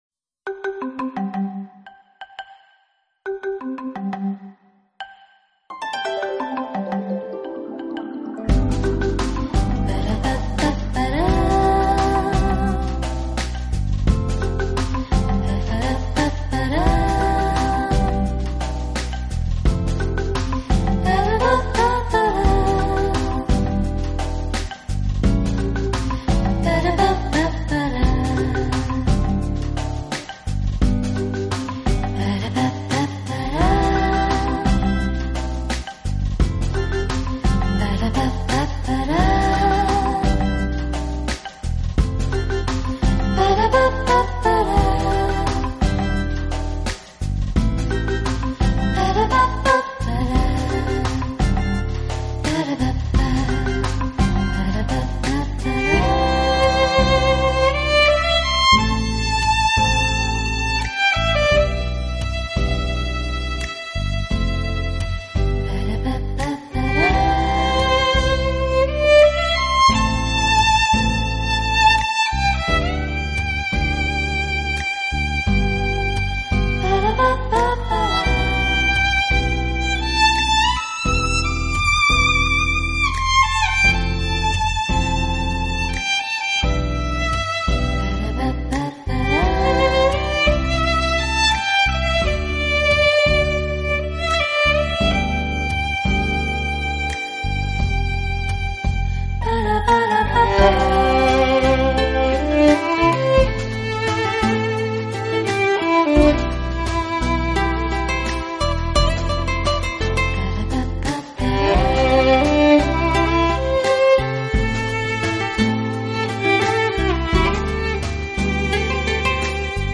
敏锐的音乐触觉、精准无暇的技巧、优美出尘的音色，在熟悉的旋律